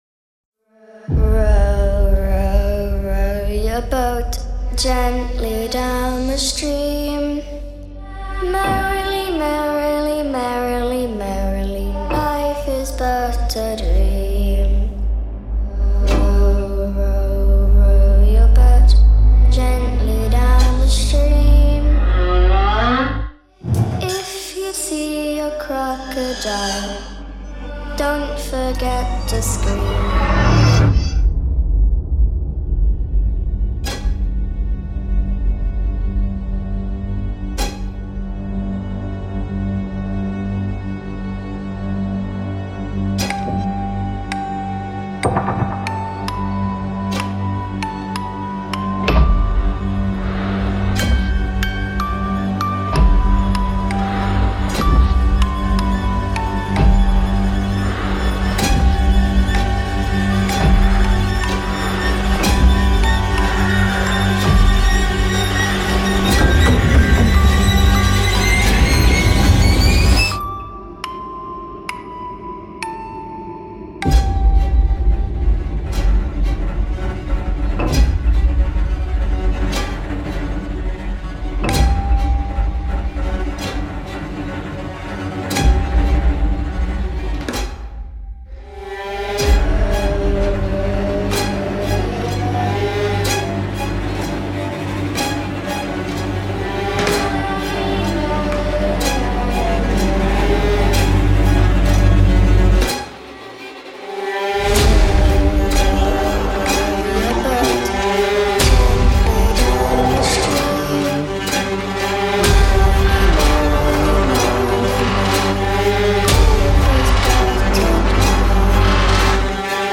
Creepy Nursery Rhymes